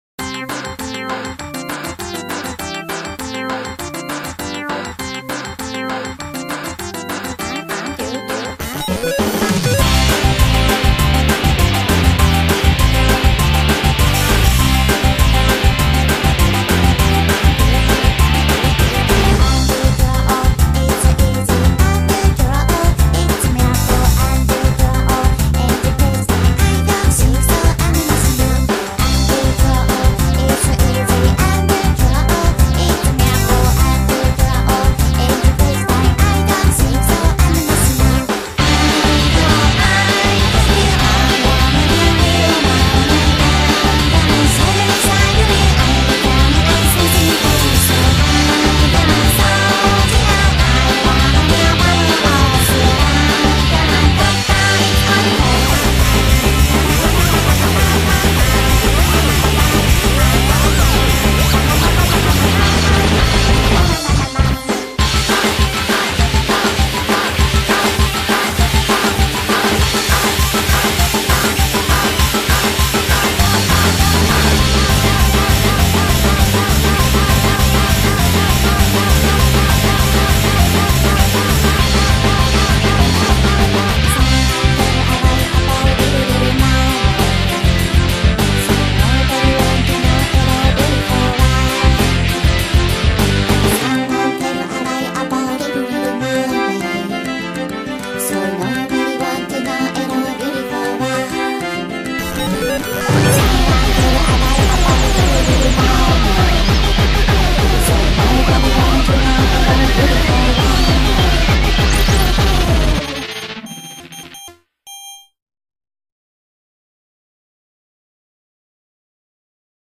BPM200-200
Audio QualityCut From Video